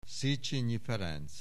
Aussprache Aussprache
SZECHENYIFERENC.wav